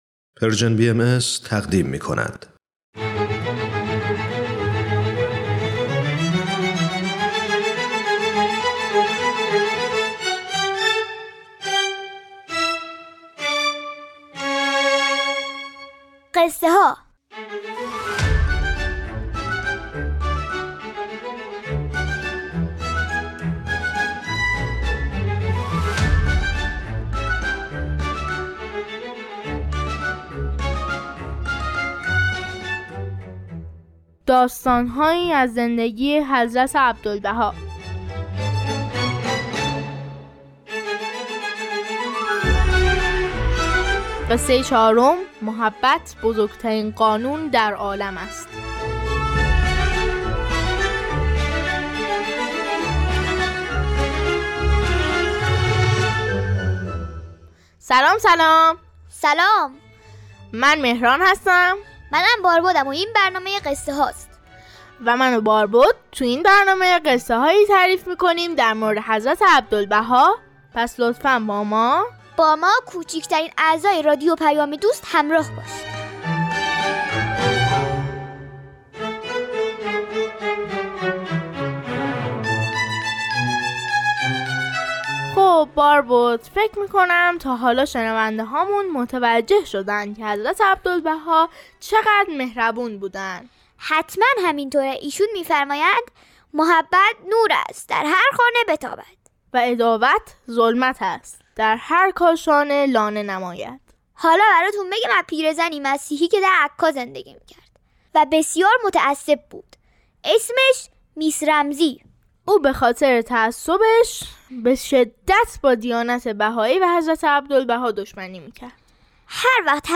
قصه‌ها